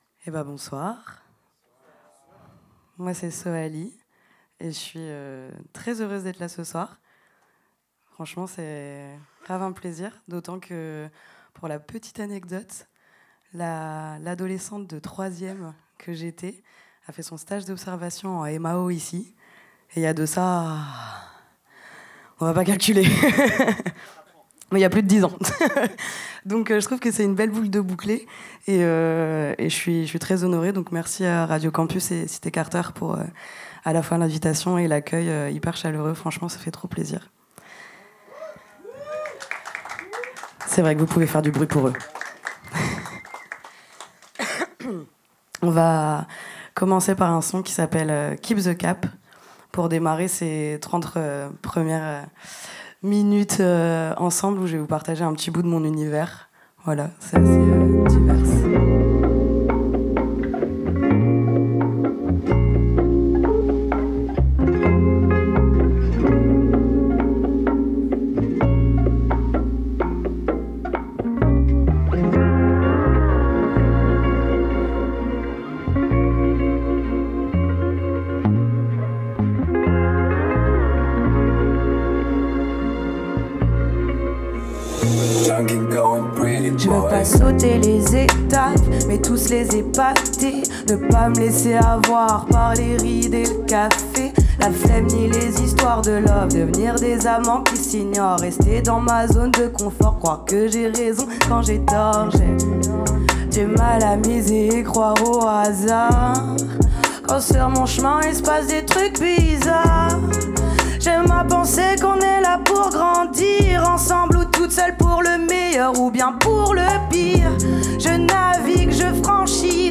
en concert sur la scène